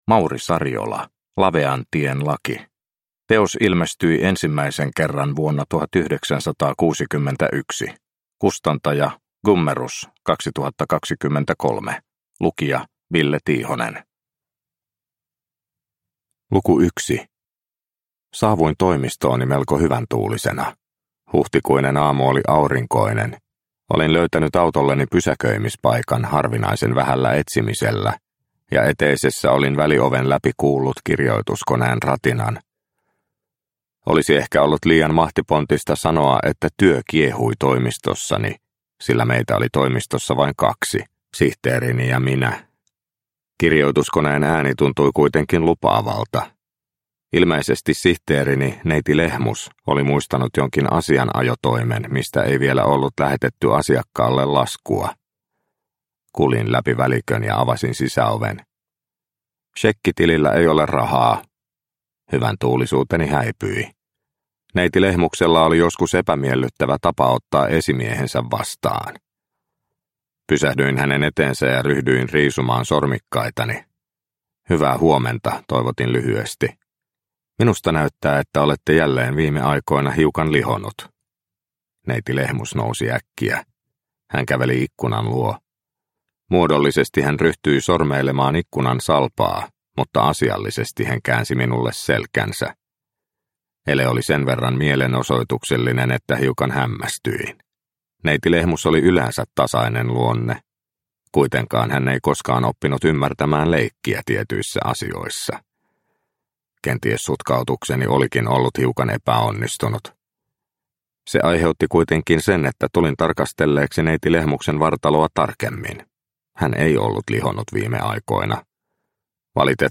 Lavean tien laki – Ljudbok